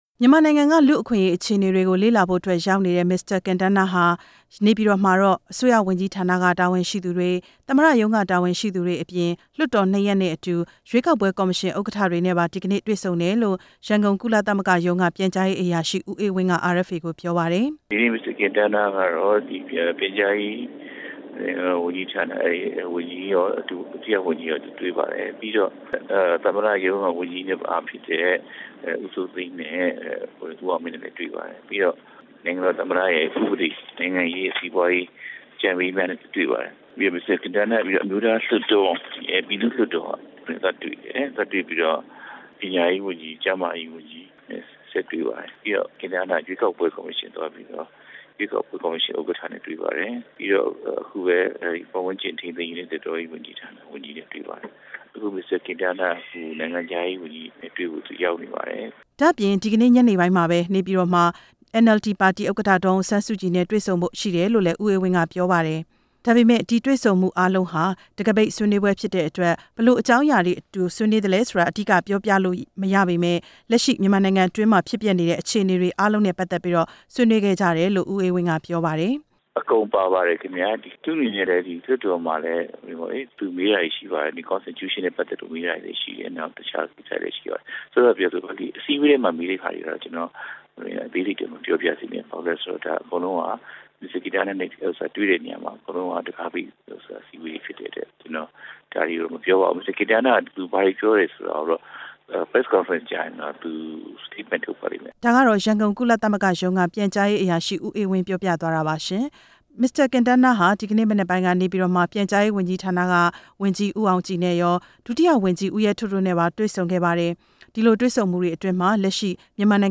မစ္စတာ ကင်တားနားရဲ့ ဒီကနေ့ခရီးစဉ် တင်ပြချက်